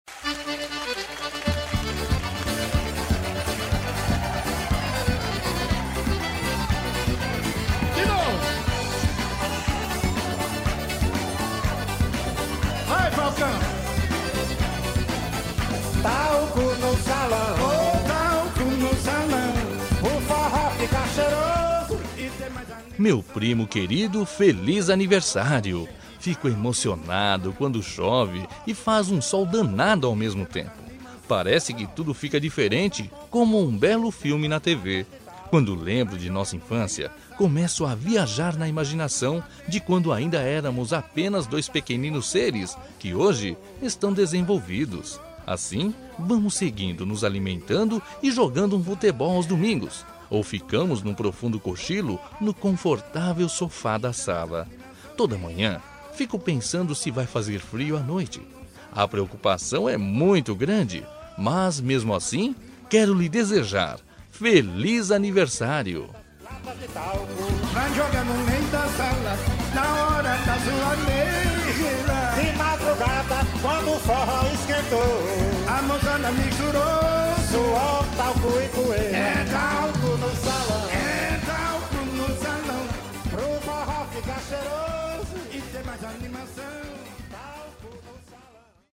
Aniversário de Humor – Voz Masculina – Cód: 200212